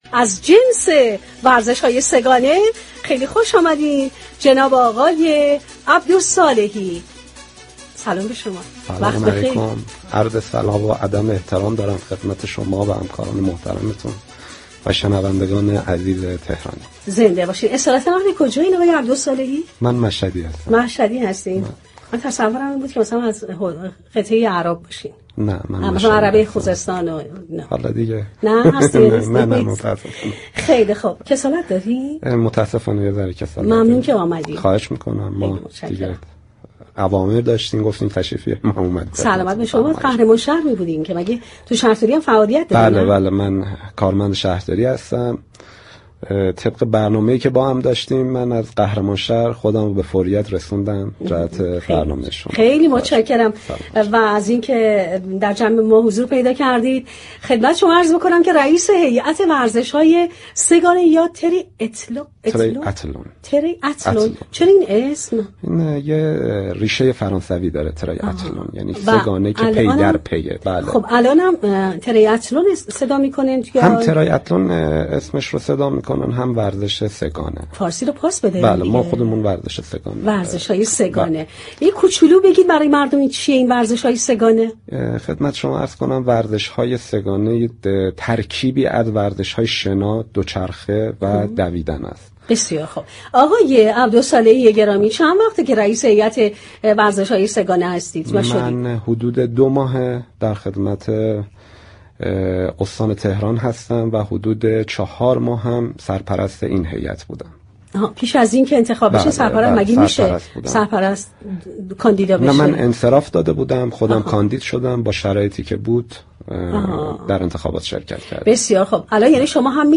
در گفت‌وگو با برنامه «تهران ورزشی»رادیو تهران